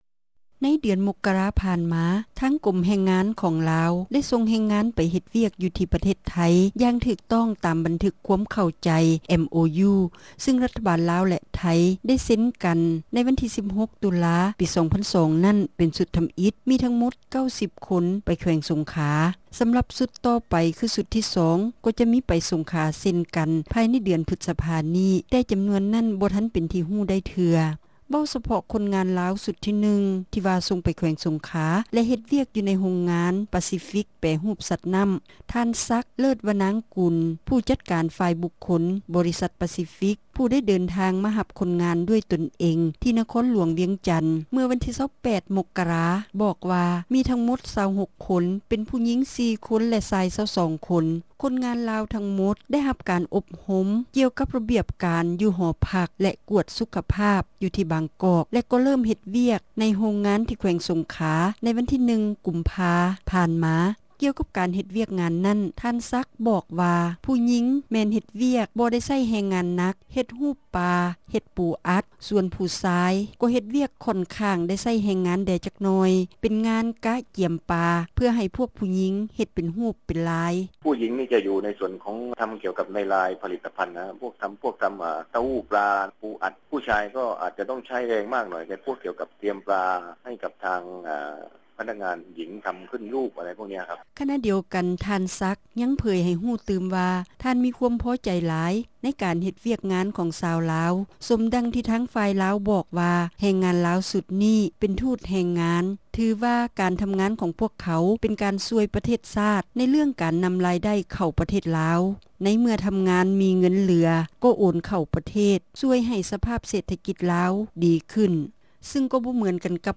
ຣາຍງານ ໂດຍ